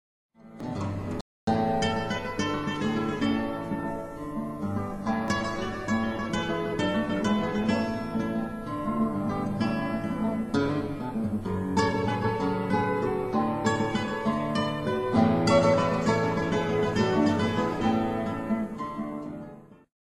Gitarren solo